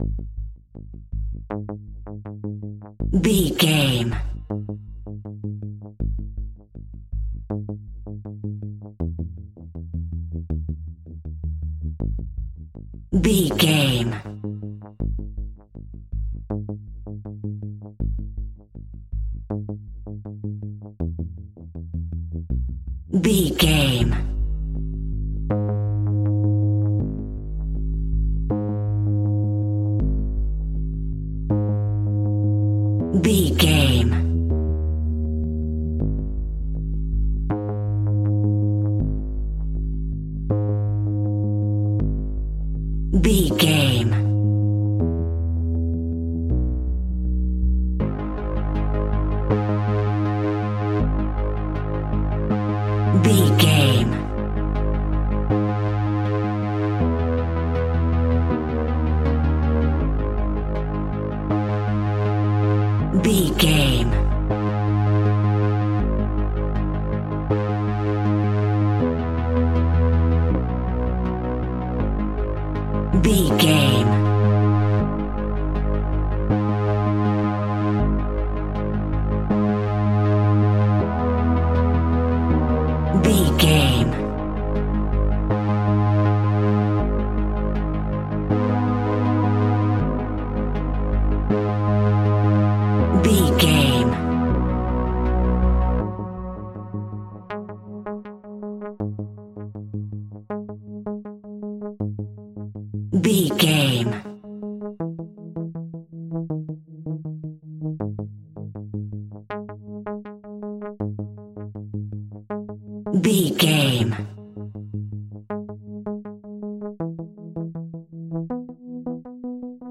Aeolian/Minor
G#
ominous
dark
haunting
eerie
driving
synthesiser
brass
horror music